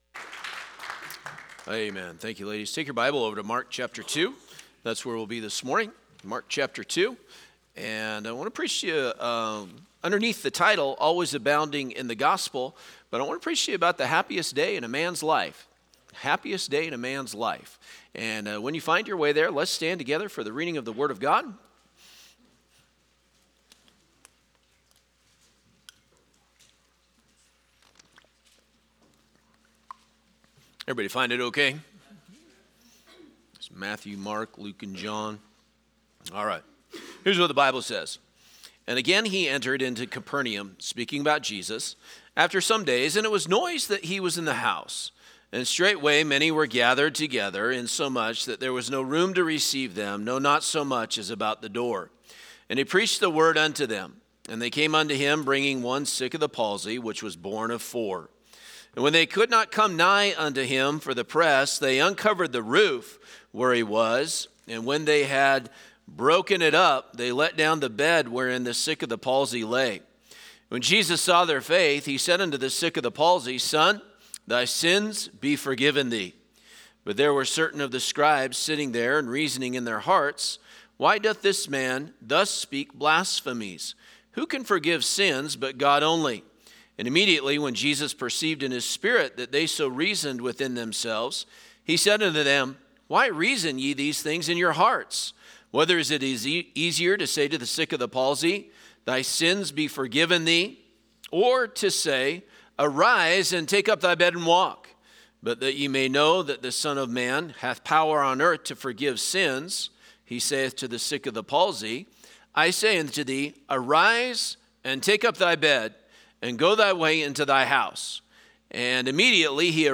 Passage: Mark 2:17 Service Type: Sunday Morning